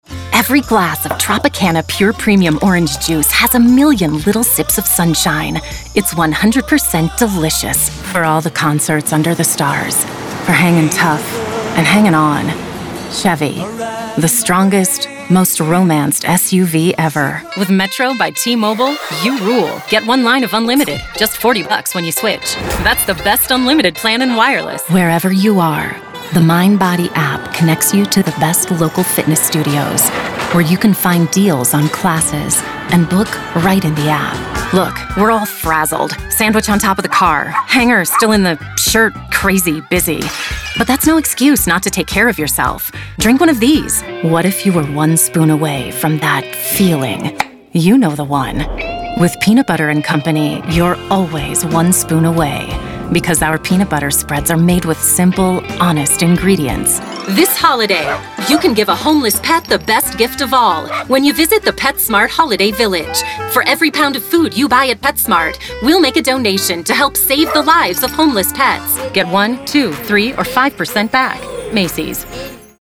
Youthful, Cool, Polished, Sophisticated
Commercial